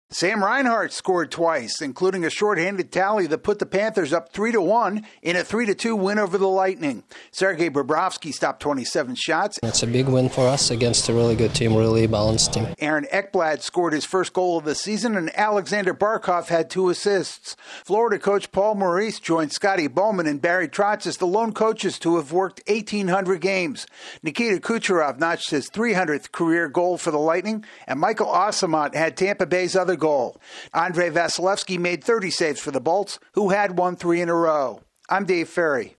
The Panthers knock off their arch rivals. AP correspondent